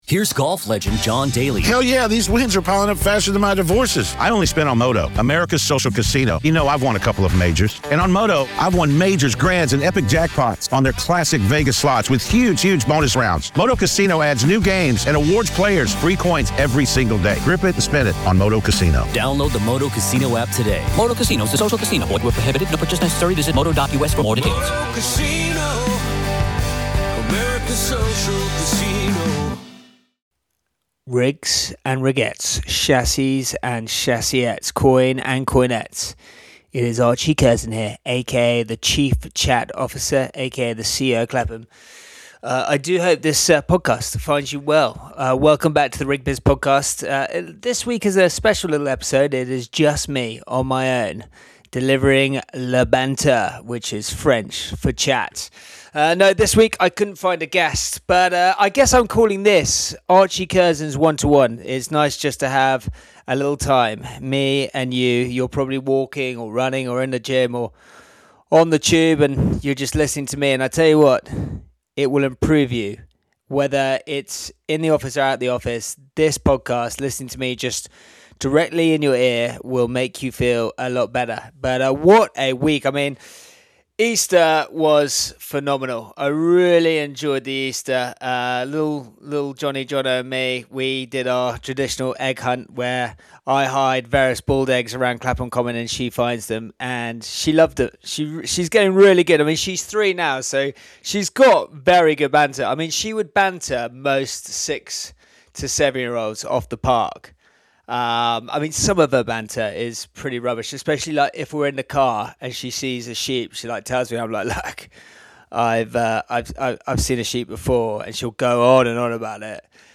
a one-to-one chat - relaxed, unfiltered, and easygoing.